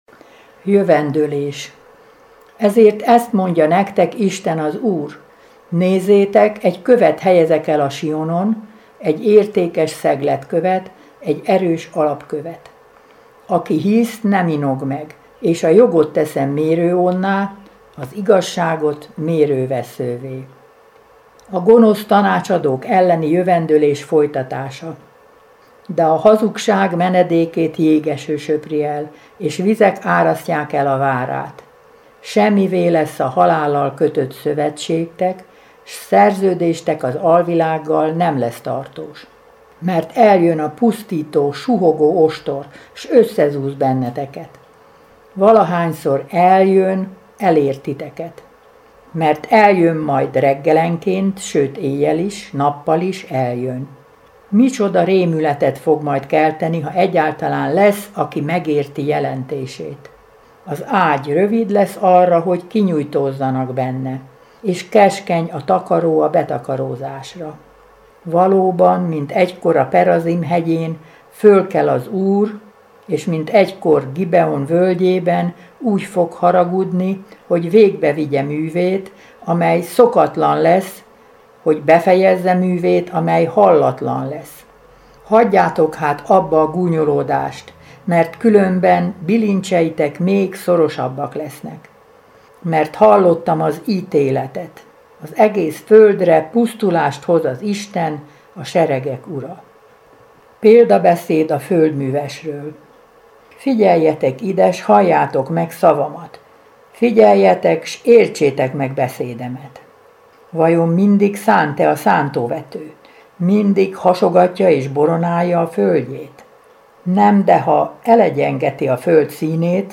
Felolvasók: a Szeged-Tarjánvárosi Szent Gellért Plébánia hívei
A felvétel a Szent Gellért Plébánián készült 2024. augusztusában